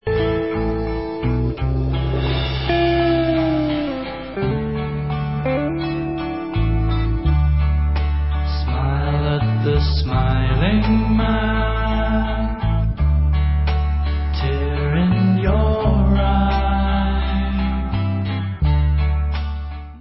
Stereo Single Version